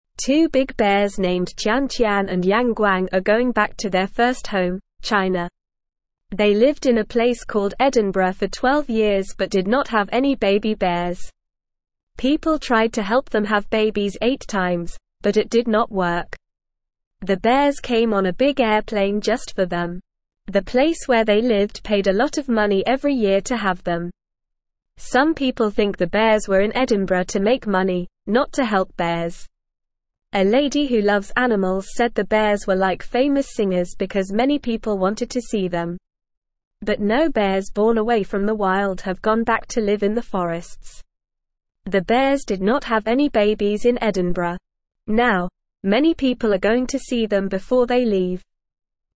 Normal
English-Newsroom-Beginner-NORMAL-Reading-Big-Bears-Tian-Tian-and-Yang-Guang-Go-Home.mp3